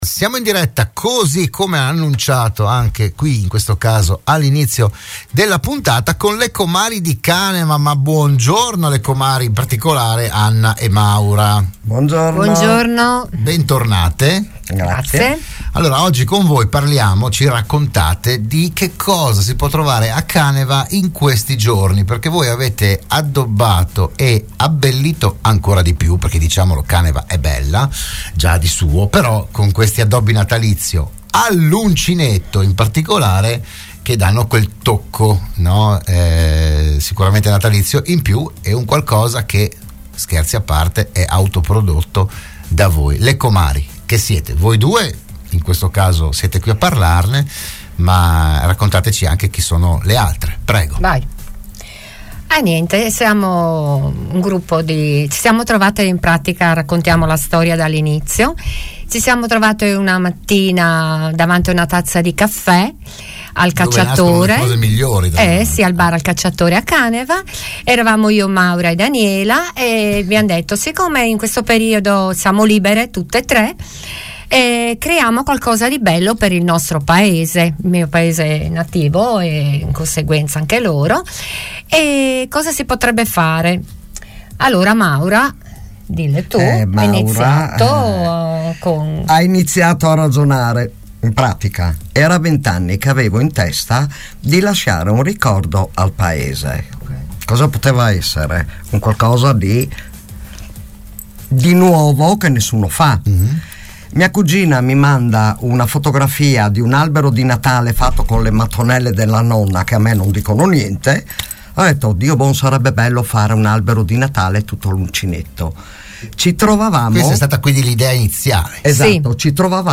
Se n'è parlato oggi a "RadioAttiva" di Radio Studio Nord